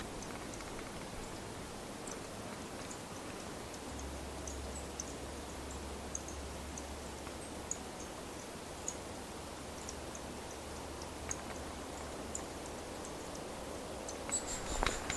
Aphrastura spinicauda
Una bandada de al menos 6 ejemplares realizando un contacto mientras recorrian los arboles a baja altura.
Nome em Inglês: Thorn-tailed Rayadito
Localidade ou área protegida: Glaciar Martial
Certeza: Observado, Gravado Vocal
rayadito-contacto.mp3